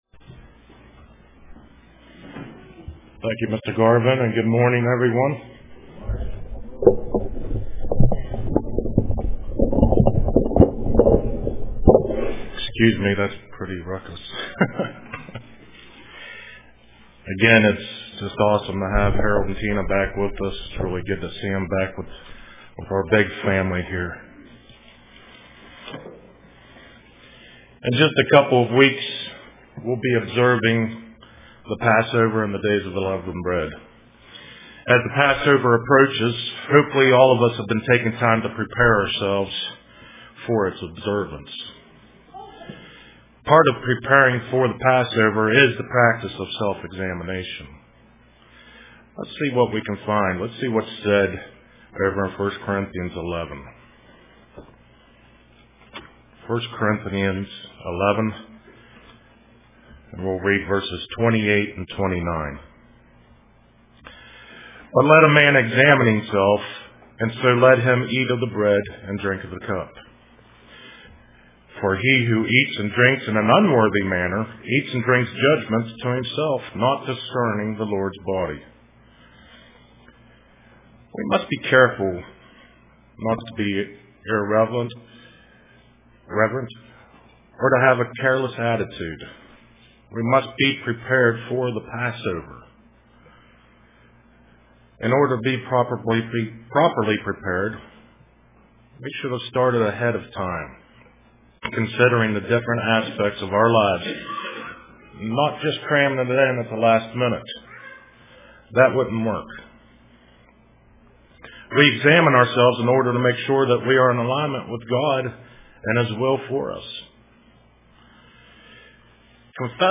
Print Compassion UCG Sermon Studying the bible?